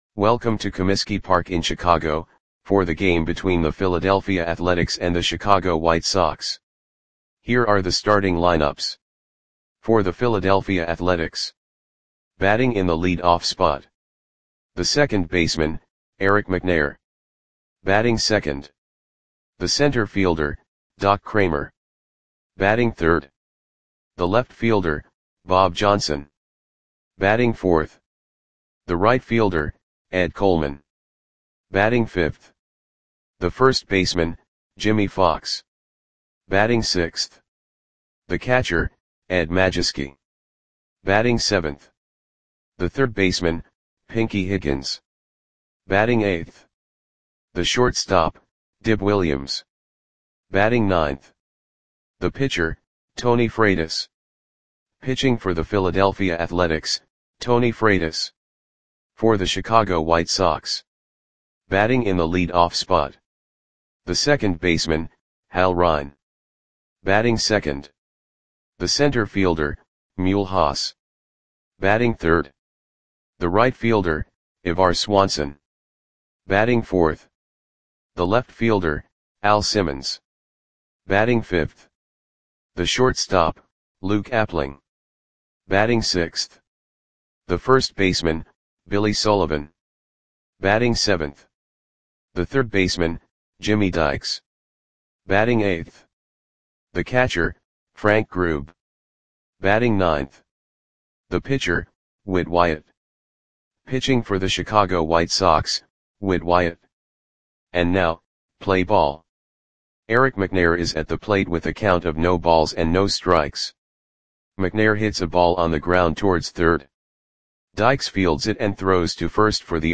Audio Play-by-Play for Chicago White Sox on June 26, 1933
Click the button below to listen to the audio play-by-play.